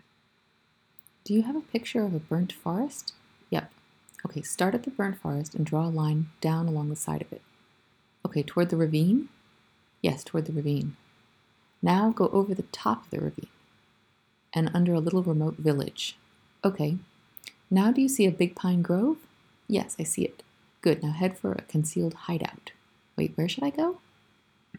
The MapTask dialogues are unscripted (hence, natural conversation) while structurally similar (hence, easier to compare with each other). Each dialogue consists of a conversation between a pair of participants, each with a map in front of them.
Here is a sample dialogue between two participants working with the maps below: